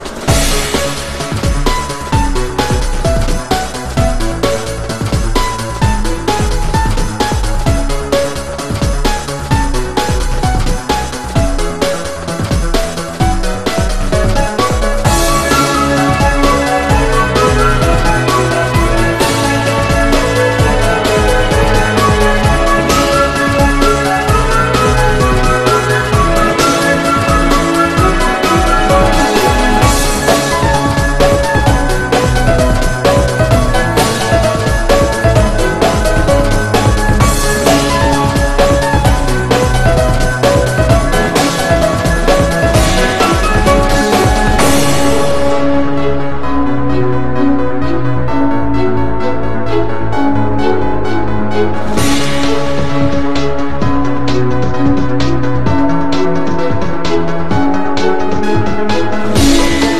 stikman teleport sound effects free download